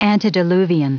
Prononciation du mot antediluvian en anglais (fichier audio)
Prononciation du mot : antediluvian